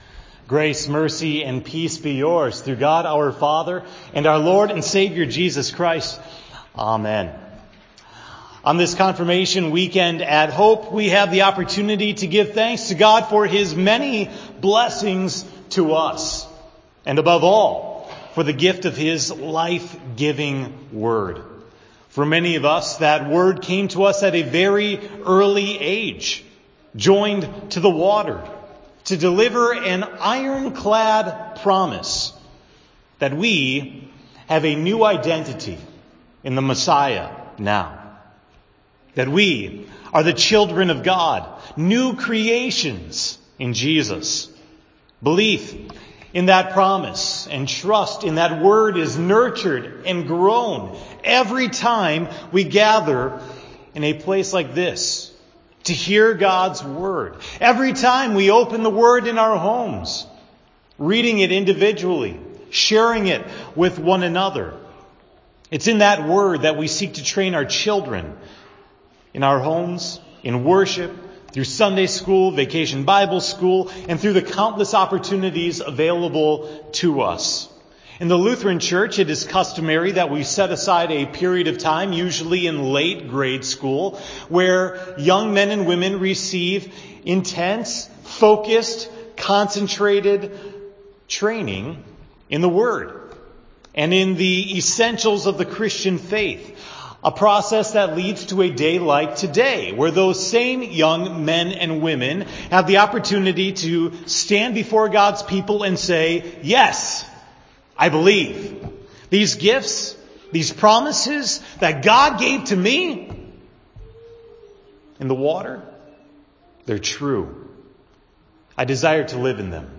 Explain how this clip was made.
The sermon for May 6, 2018 (Confirmation Sunday) at Hope Text: 1 John 5:1-8